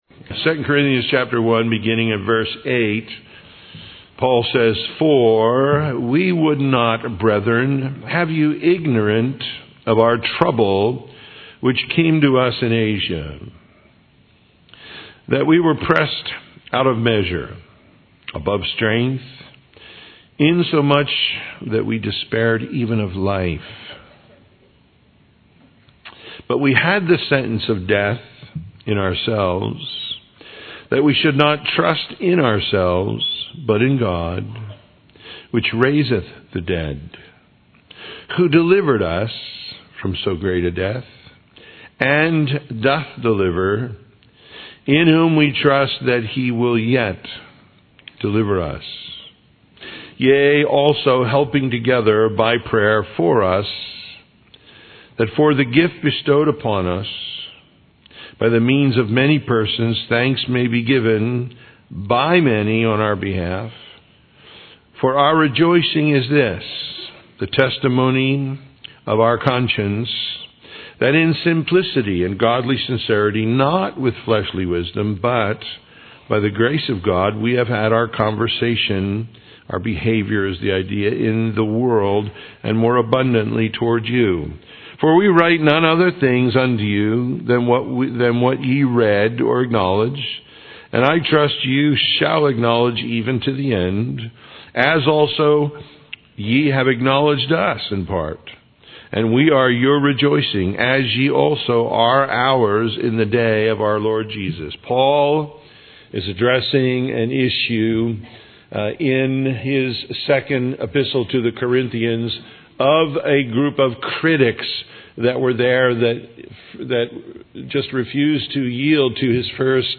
Original Teaching